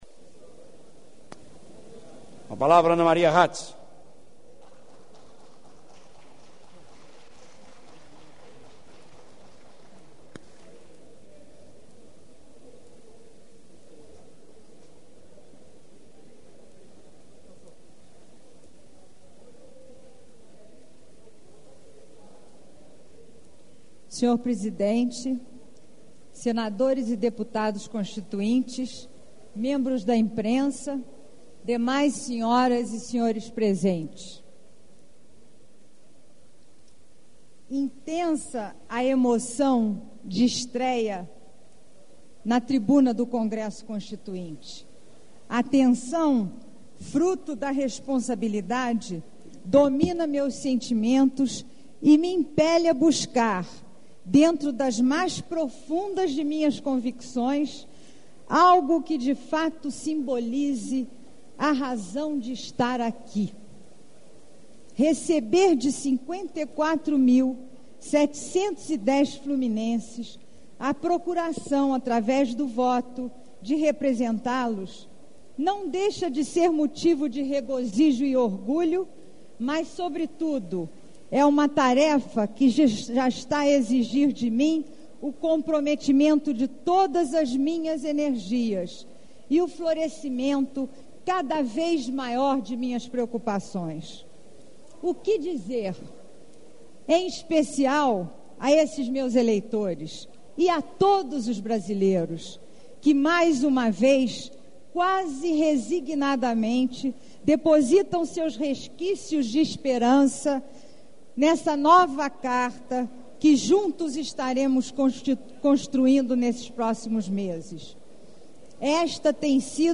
- Discurso pronunciado em 19 de fevereiro de 1987 - Atuação Parlamentar